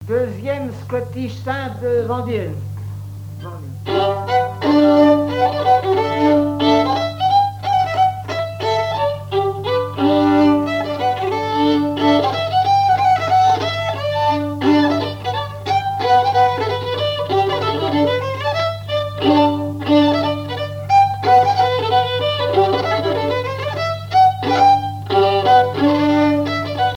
Scottish simple vendéenne
Chants brefs - A danser
danse : scottich trois pas
Pièce musicale inédite